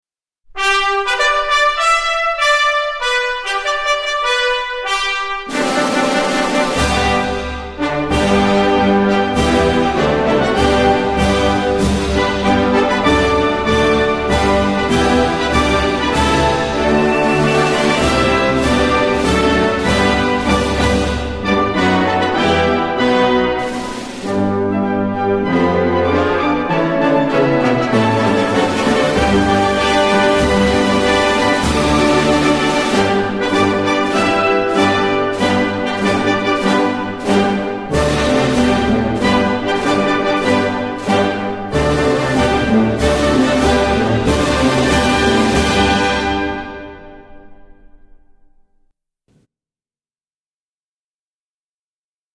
管乐合奏：中华人民共和国国歌